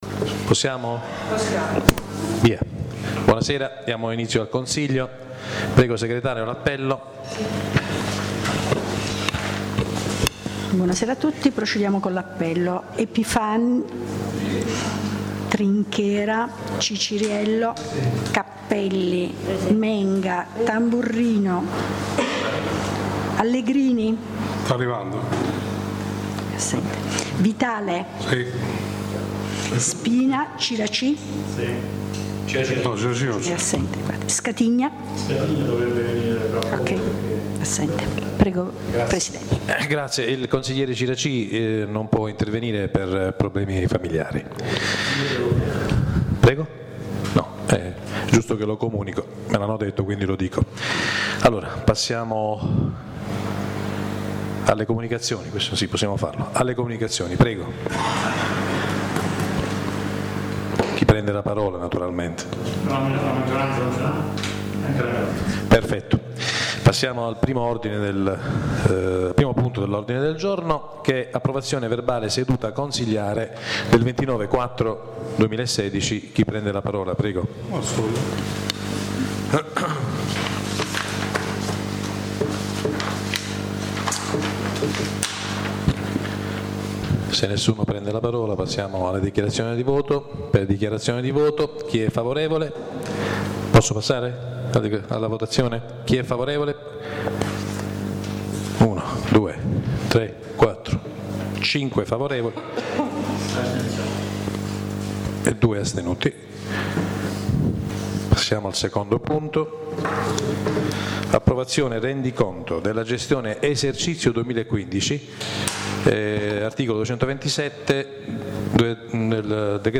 La registrazione audio del Consiglio Comunale di San Michele Salentino dell’11/05/2016: